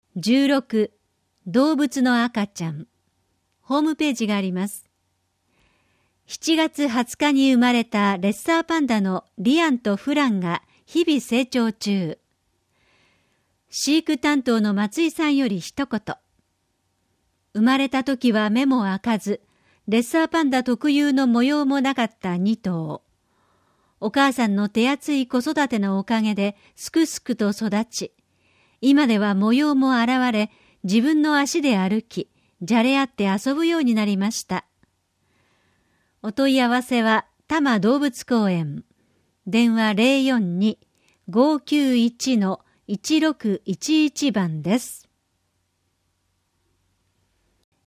「広報東京都音声版」は、視覚に障害のある方を対象に「広報東京都」の記事を再編集し、音声にしたものです。